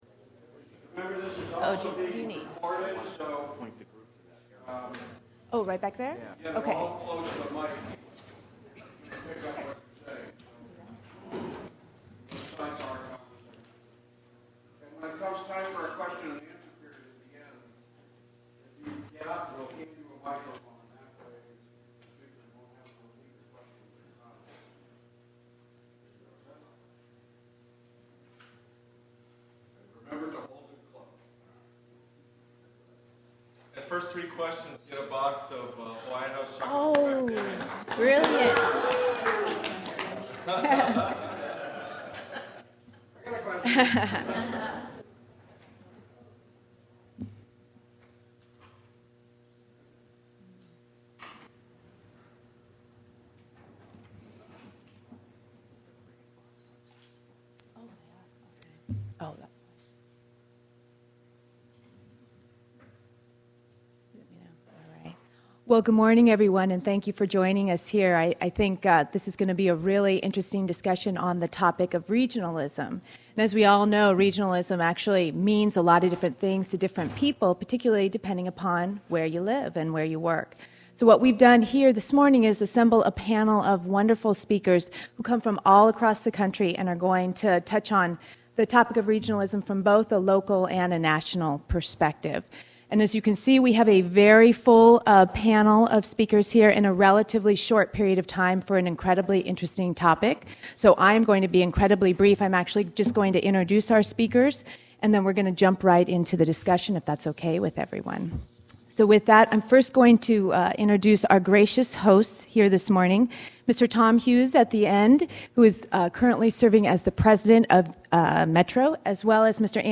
ABA Presentation – Regionalization of Planning Decisions: The Hawaii Model